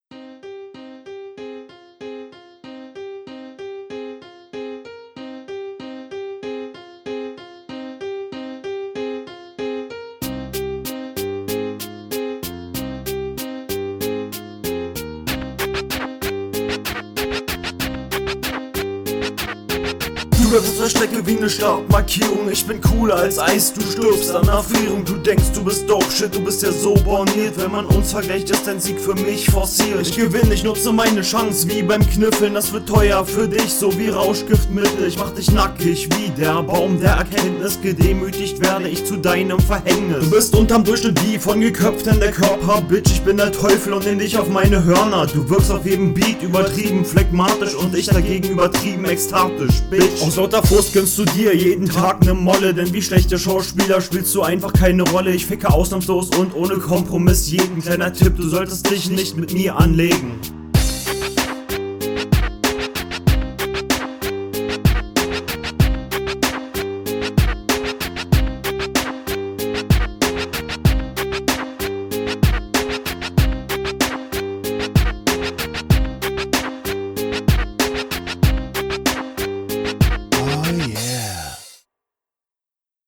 War tatsächlich schon besser vom Flow, textlich dennoch nicht viel was hängen blieb
Beat ist cool, Soundqualität und Flow auch hier schlecht aber besser als in der anderen …